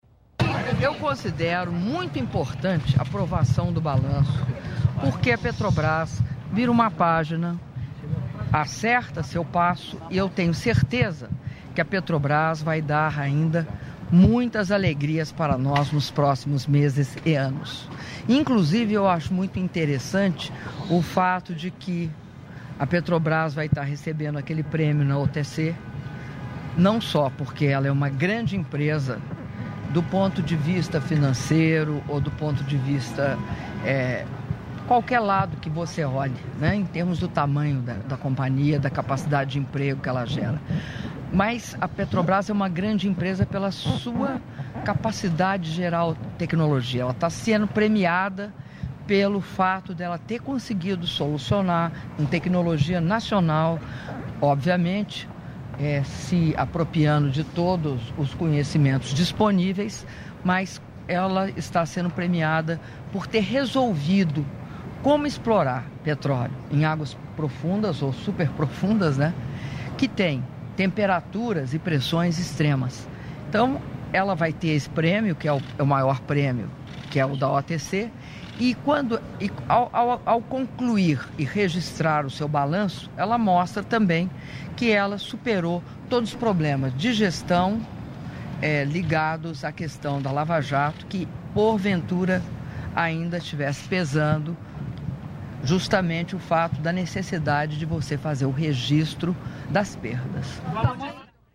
Áudio da entrevista coletiva concedida pela Presidenta da República, Dilma Rousseff, após almoço em homenagem à Presidenta da República da Coreia, Park Geun-hye (01min41s) — Biblioteca